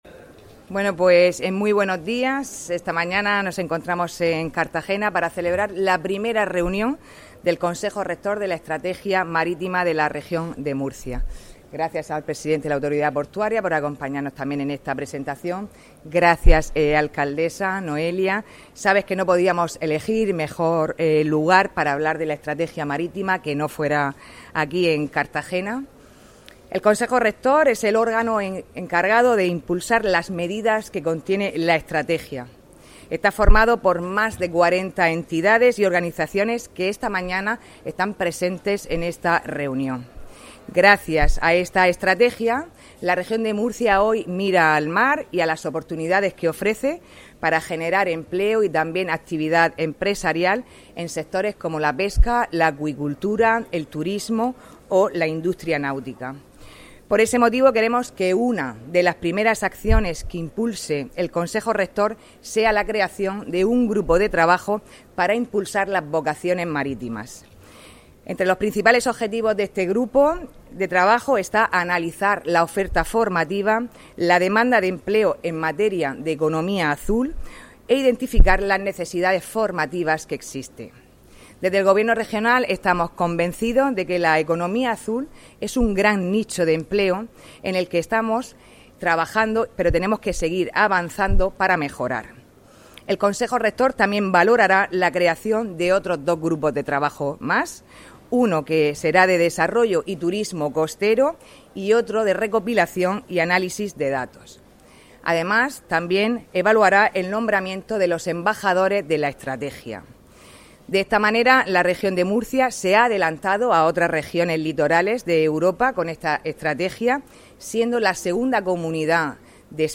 Audio: Reuni�n constitutiva del Consejo Rector de la Estrategia Mar�tima de la Regi�n de Murcia (MP3 - 7,97 MB)